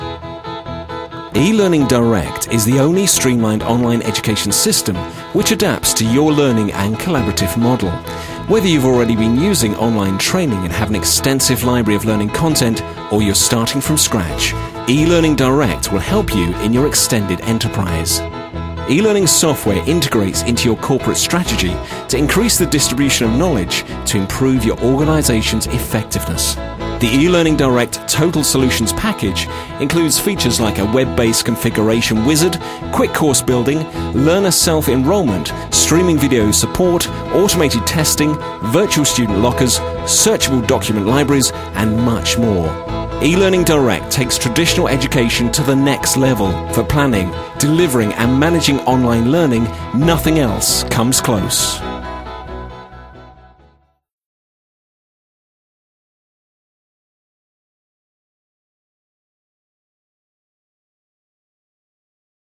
With twenty years of experience working in radio for the BBC and a rich, warm, cultured voice
ELearning
04-E-Learning-Direct-Ad_.mp3